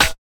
Snare (23).wav